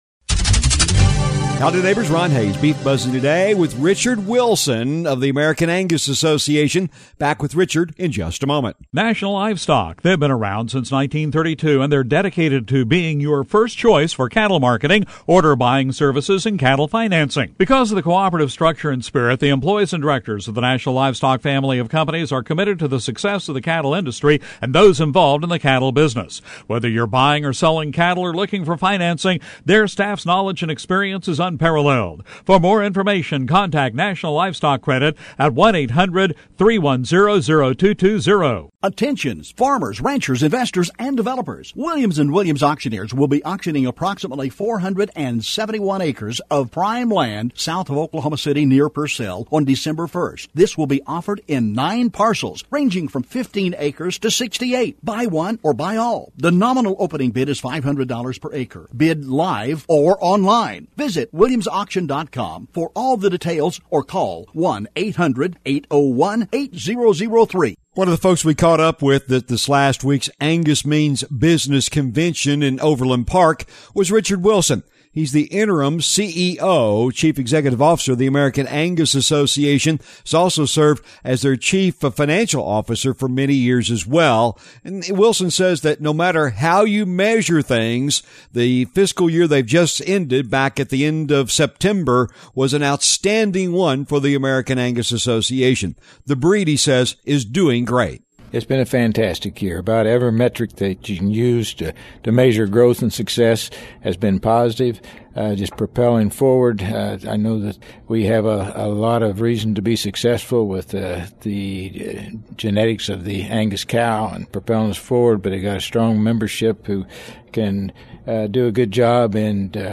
The Beef Buzz is a regular feature heard on radio stations around the region on the Radio Oklahoma Network- but is also a regular audio feature found on this website as well.